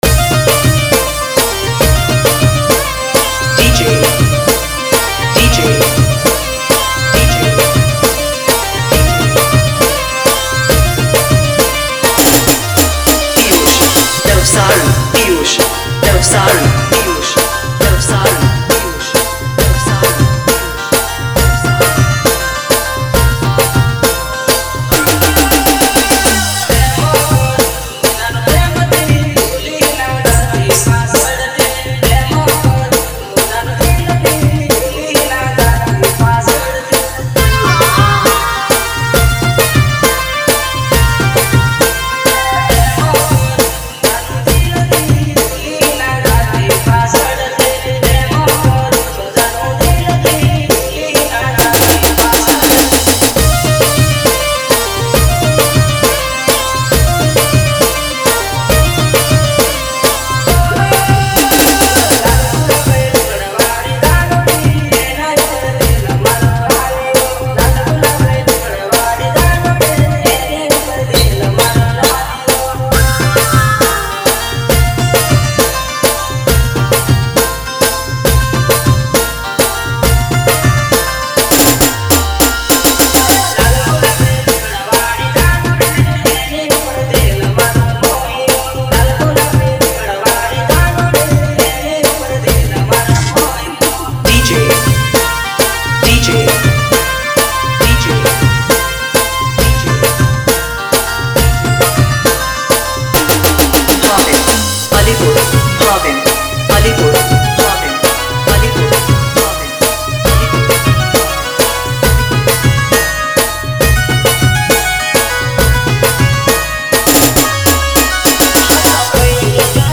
dholki